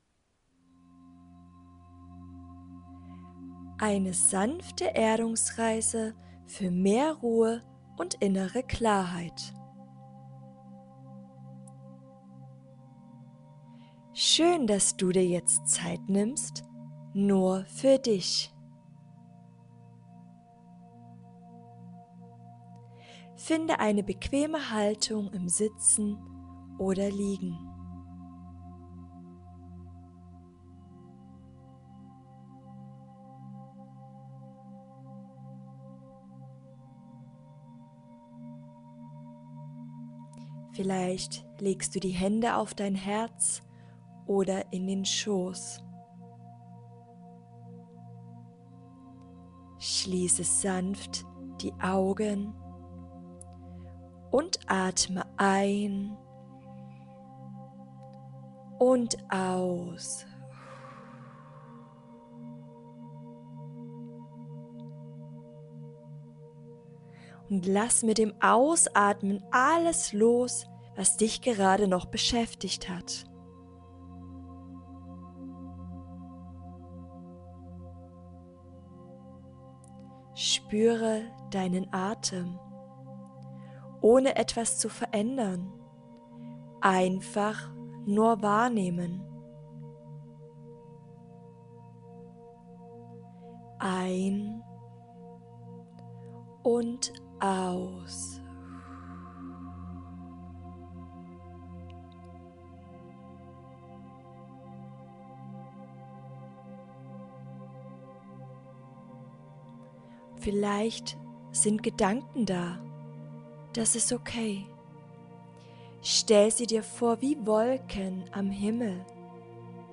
🎧 Deine geführte Meditation wartet auf dich!
Meditation_rootsandflowyoga.mp3